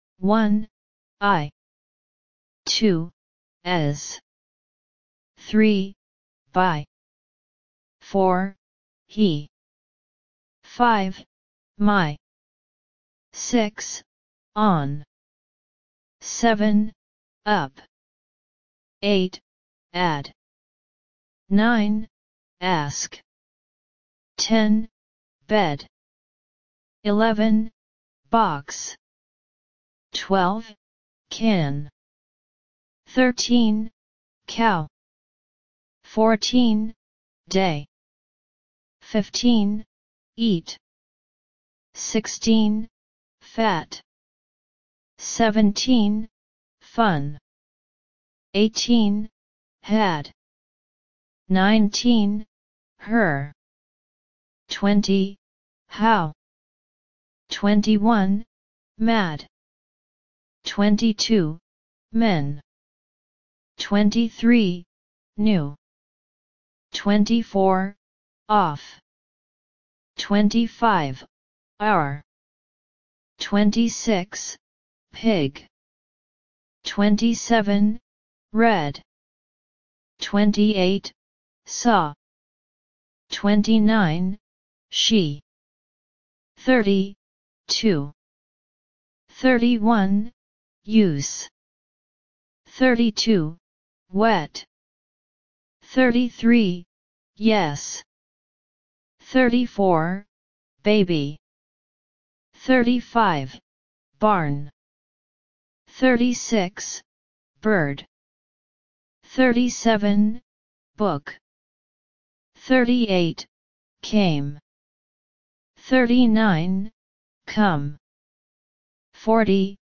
Listen and Repeat.